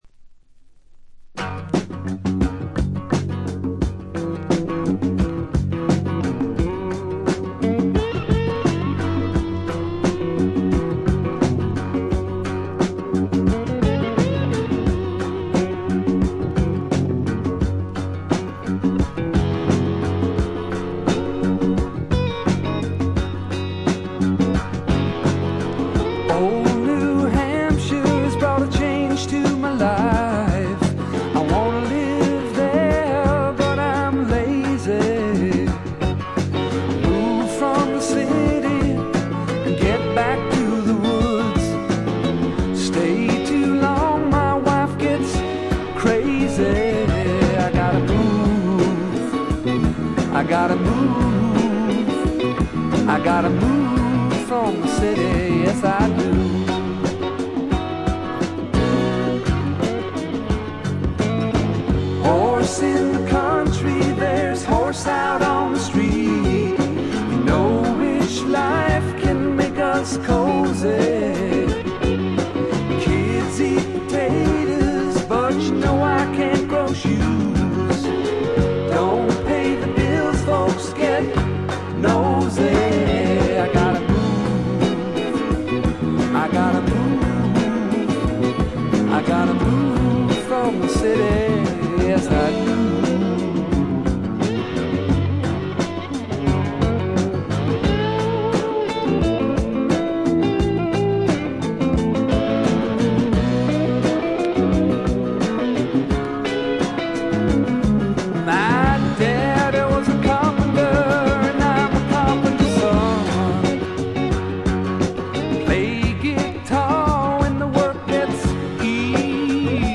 バックグラウンドノイズ、チリプチがやや多め大きめです。
試聴曲は現品からの取り込み音源です。
Vocals, Acoustic Guitar